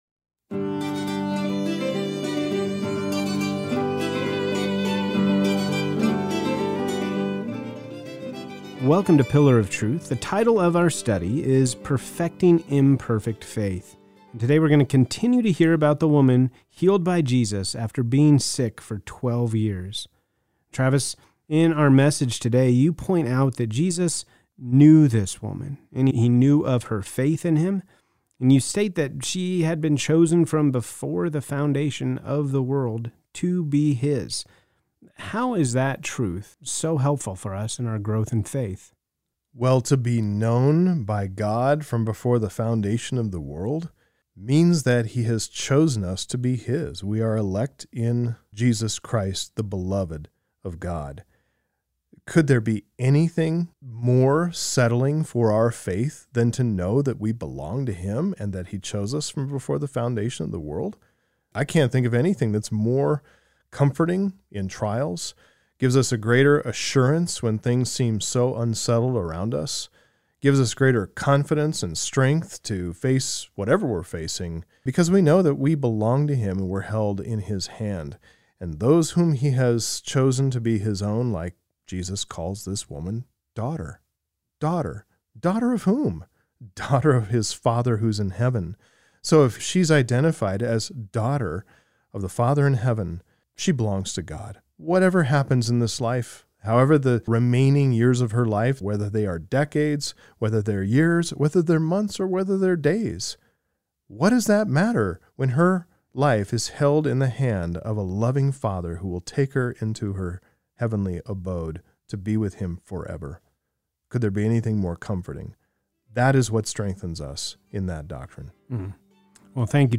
A continuing lesson on faith.